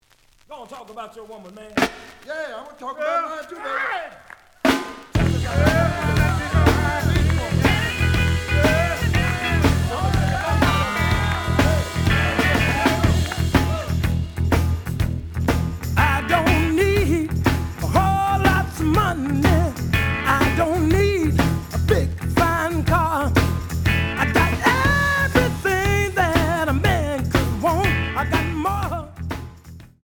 The audio sample is recorded from the actual item.
●Format: 7 inch
●Genre: Blues